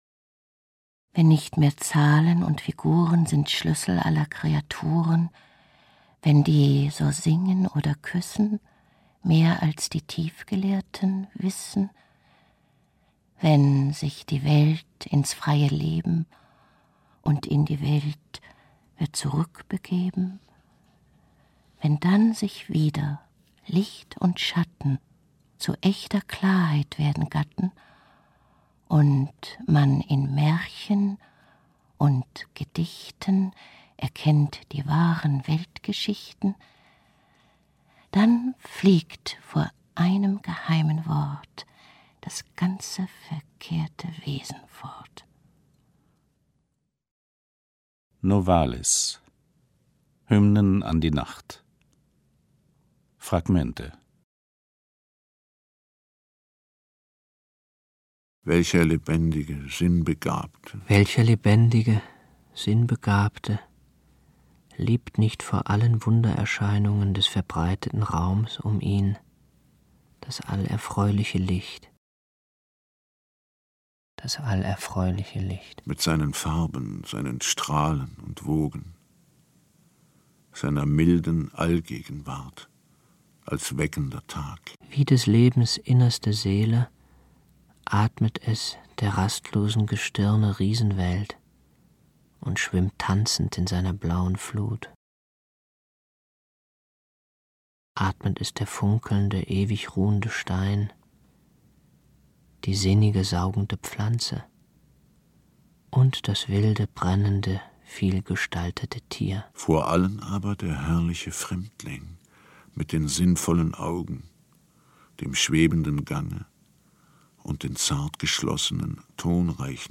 Die große Hörspiel-Edition der Romantik Hörspiele Gert Westphal , Corinna Harfouch , Jens Harzer (Sprecher)